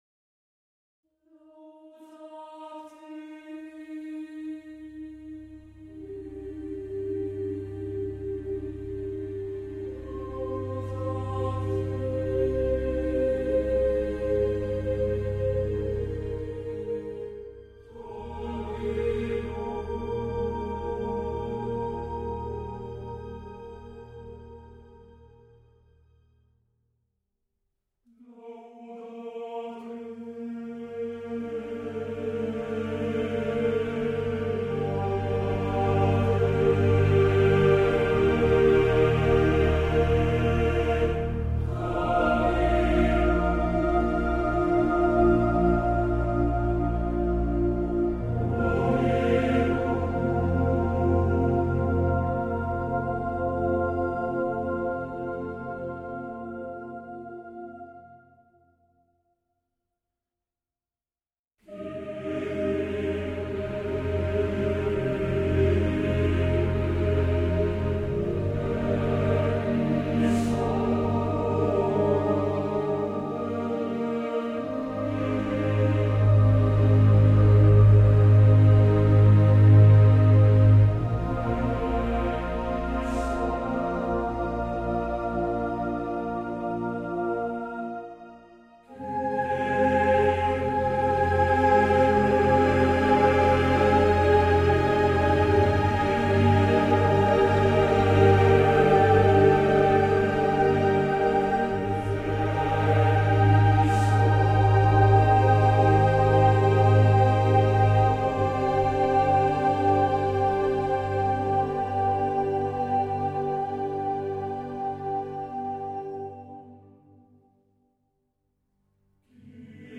for choir and strings